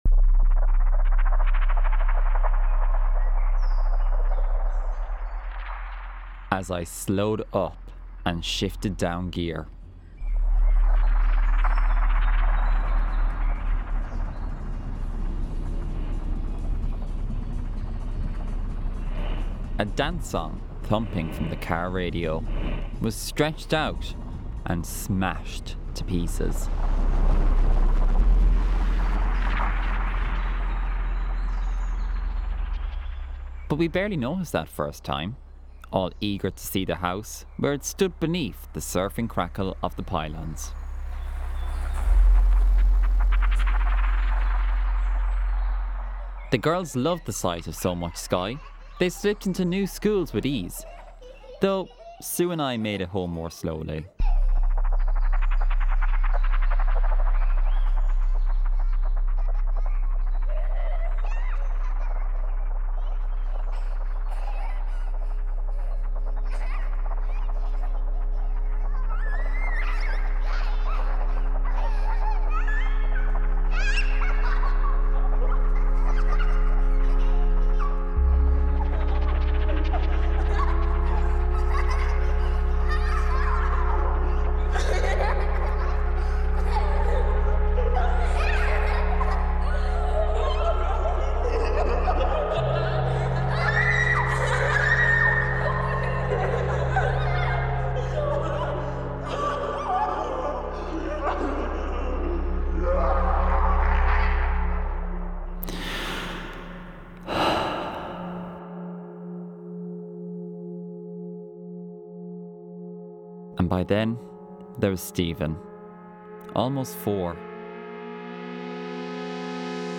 A soundscape, based on the poem ‘Air-Waves’